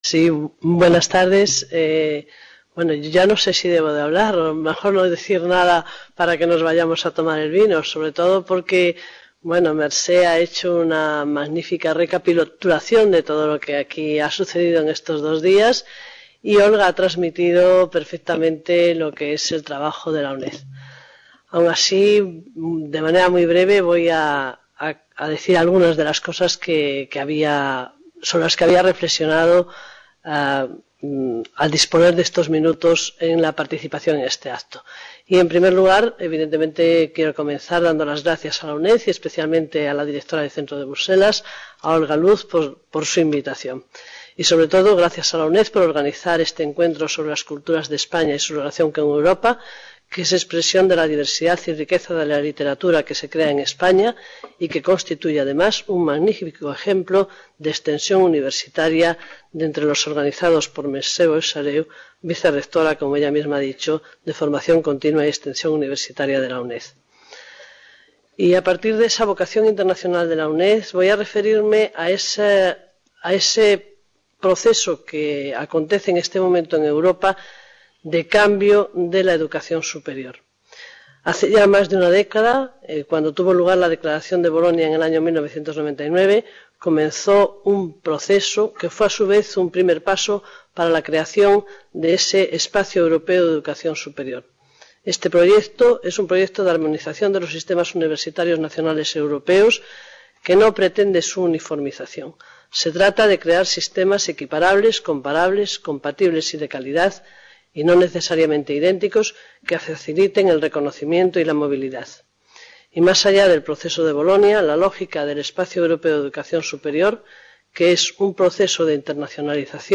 | Red: UNED | Centro: UNED | Asig: Reunion, debate, coloquio...